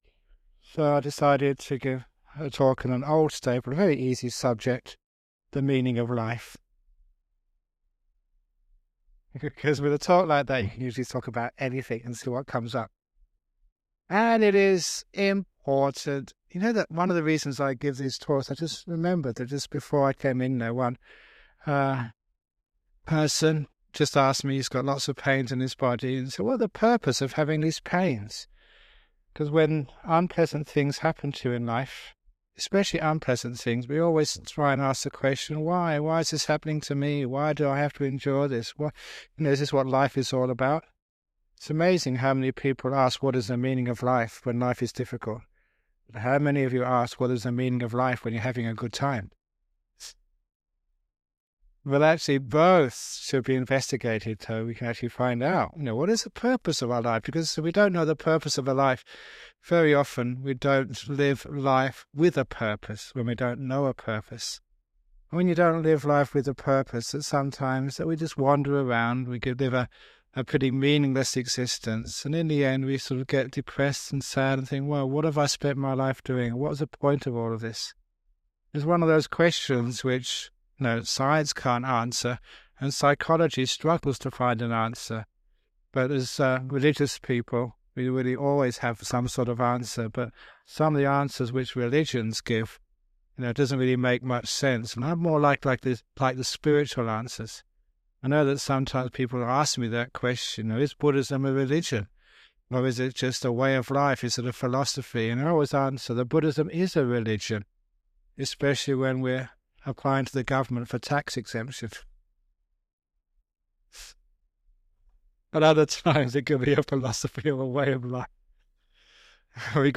During one of his recent talks, Ajahn Brahm discussed the purpose of life and how we need to question things and base our questions on our own experiences. He also shared stories to illustrate the importance of compassion, kindness, and generosity in life, citing examples of people who were willing to give selflessly, even in difficult situations.